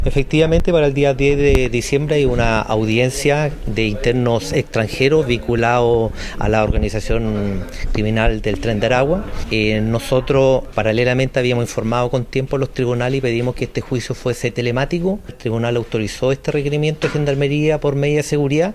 En estos últimos, se analizan los numerosos riesgos que generaría el traslado de los acusados a la audiencia, tal como lo precisó el director regional de Gendarmería, coronel Néstor Flores.